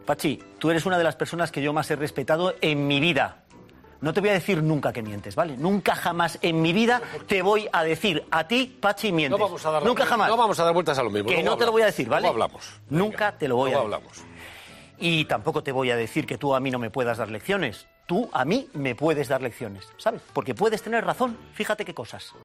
Edmundo Bal dirigiéndose a Patxi López durante el primer debate electoral de la Cadena COPE